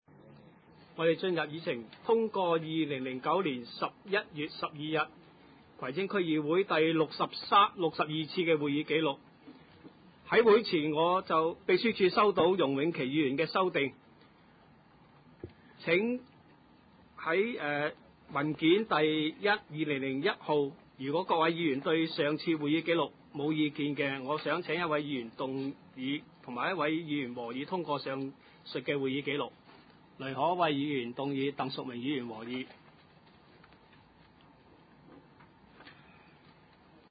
葵青區議會第六十三次會議